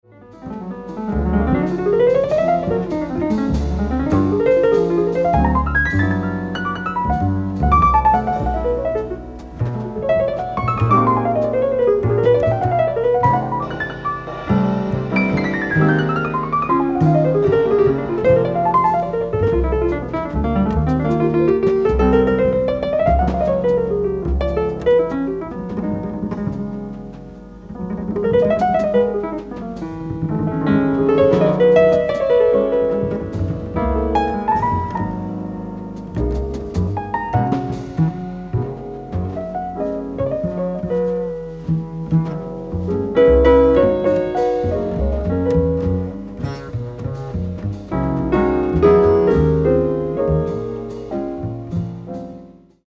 ライブ・アット・コペンハーゲン、デンマーク
※試聴用に実際より音質を落としています。